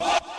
Southside Vox (4).wav